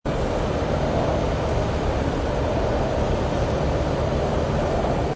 ambience_hispania.wav